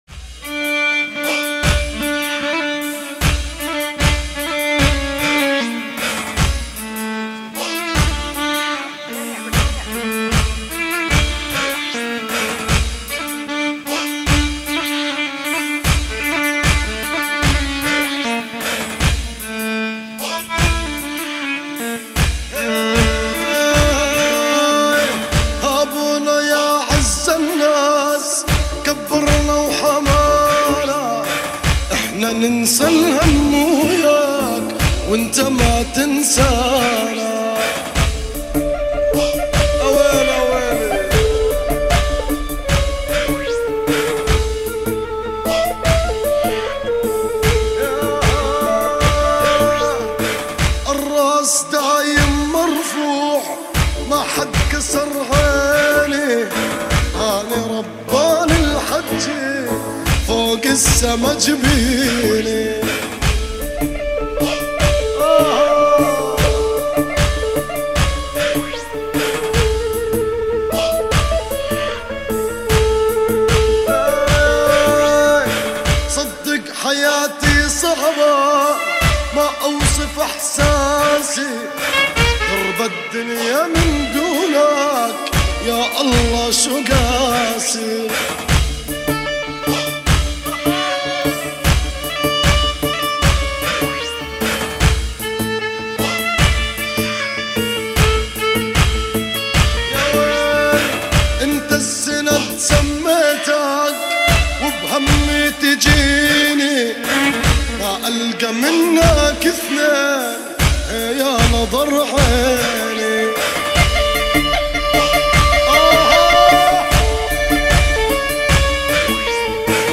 • بينما المهنة: مطرب شعبي